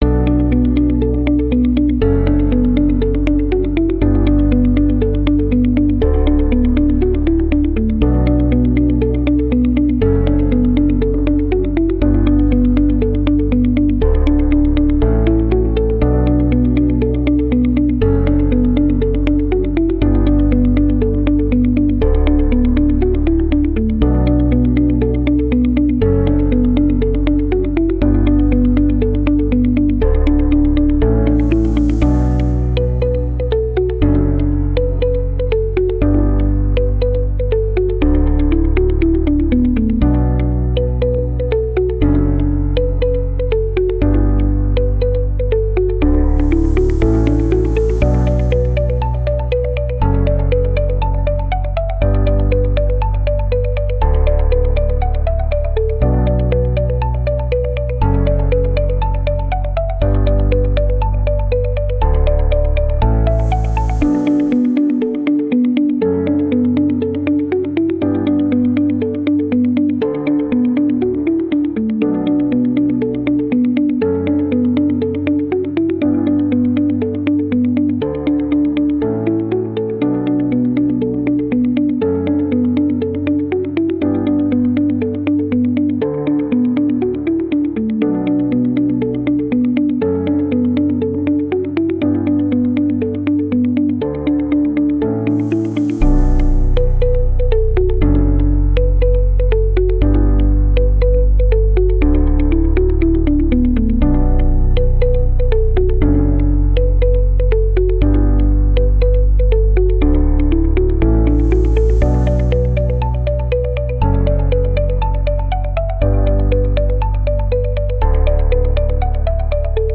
• Категория: Детские песни / Музыка детям 🎵